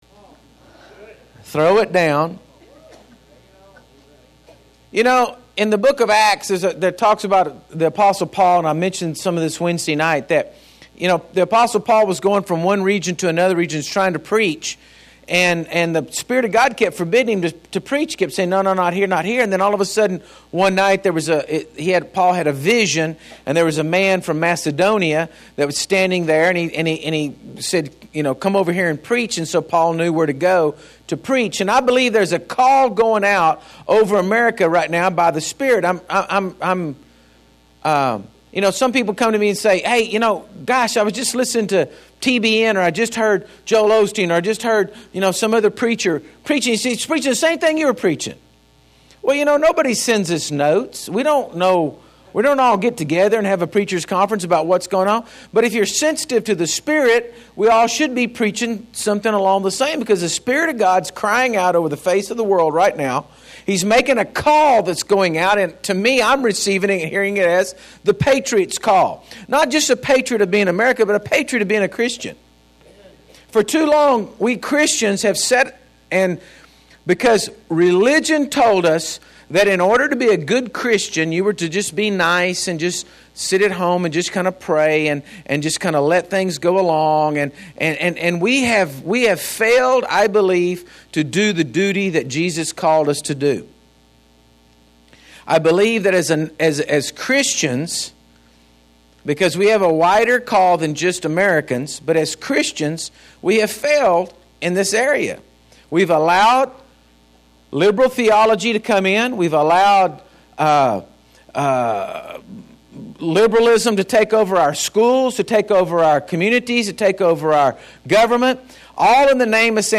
Sunday Services